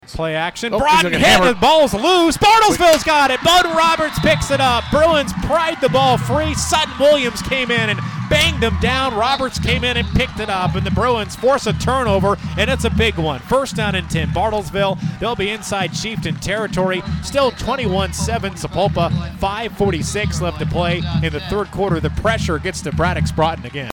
Sack Fumble.mp3